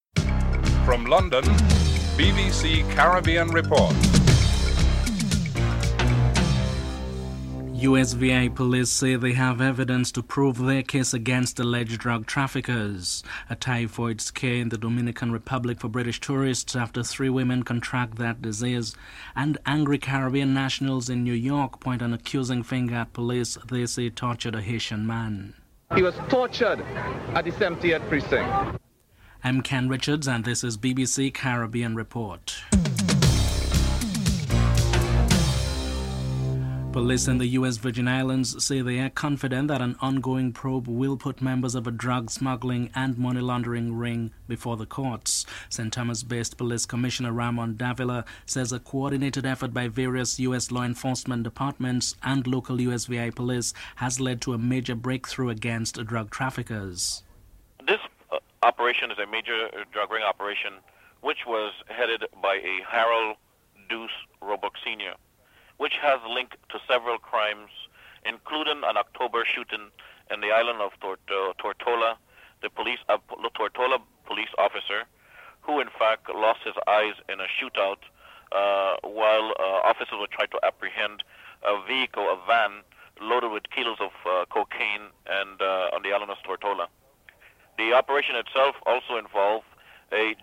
1. Headlines (00:00-00:35)
Prime Minister Kenny Anthony is interviewed (12:18-13:02)